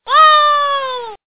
One of Toad's voice clips in Mario Kart: Super Circuit